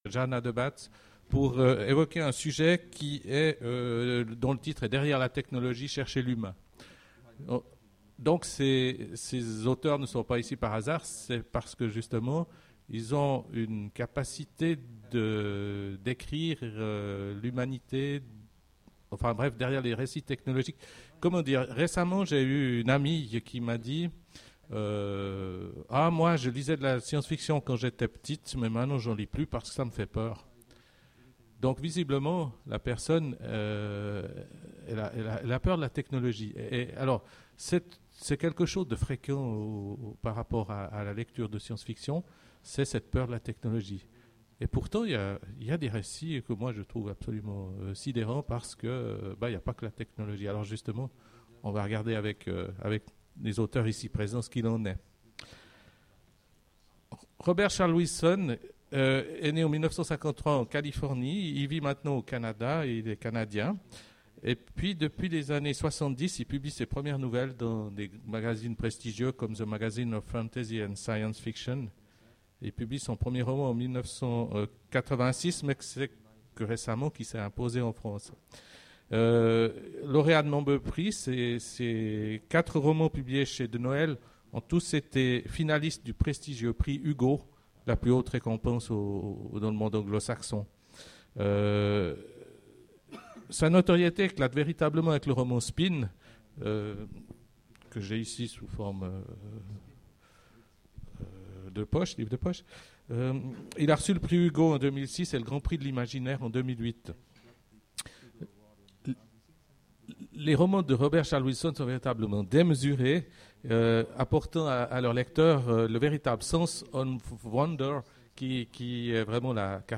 Voici l'enregistrement de la conférence Déclin de la science fiction, essor de la fantasy ? aux Imaginales 2010